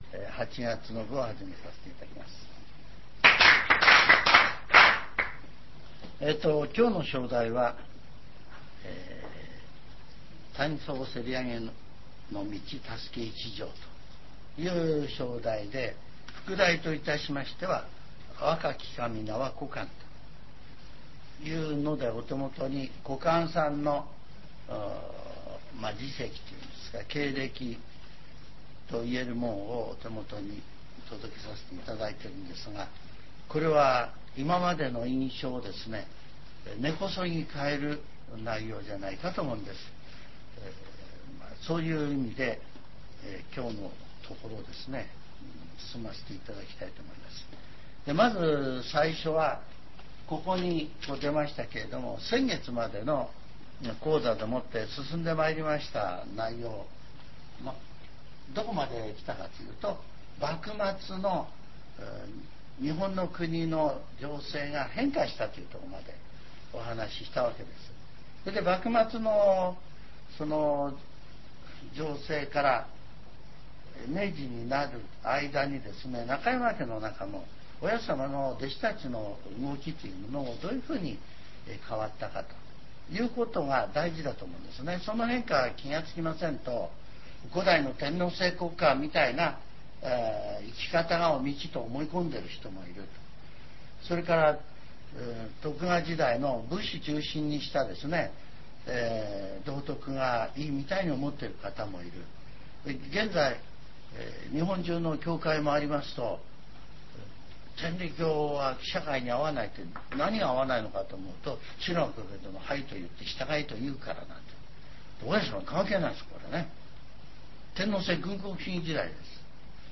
全70曲中24曲目 ジャンル: Speech